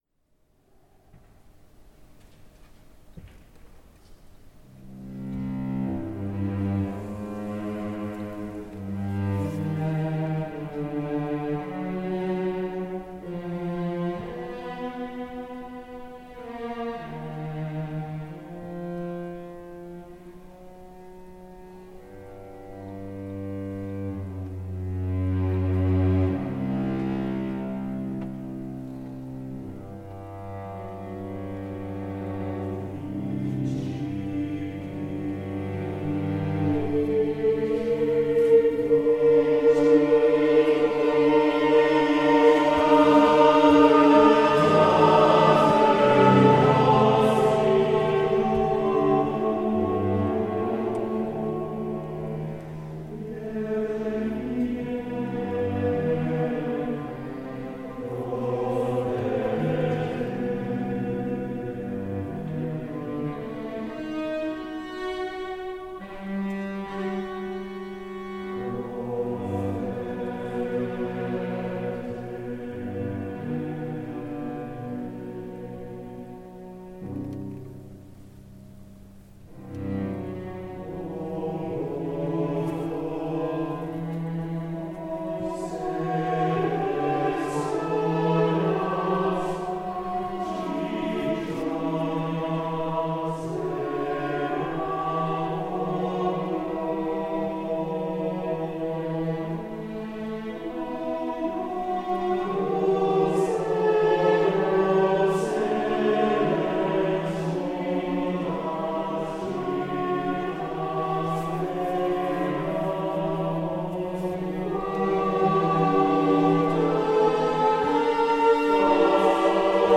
As we begin to wrap up our second week of pilgrimage to Canterbury and Salisbury Cathedrals, we’d like to share some audio selections from Canterbury Cathedral during evensong services sung last week, including the Office of Compline as performed in the crypt:
Cello
Lamentations_Canterbury-9-13.mp3